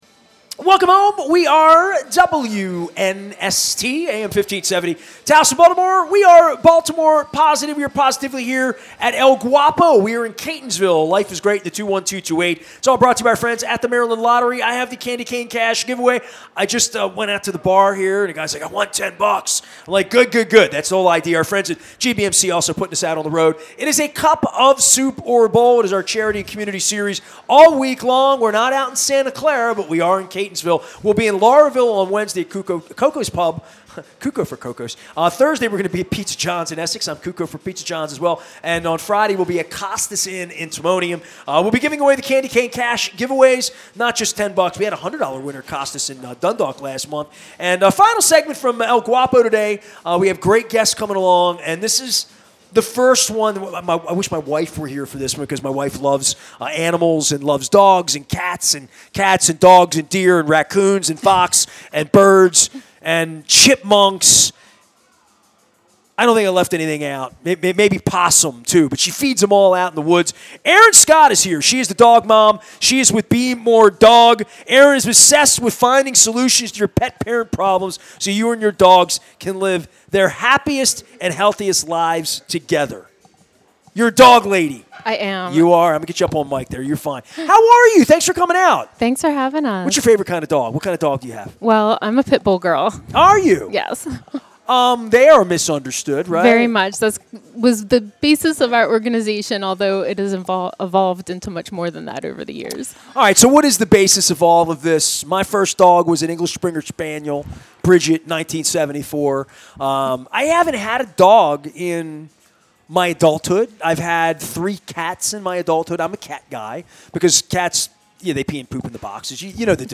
at El Guapo in Catonsville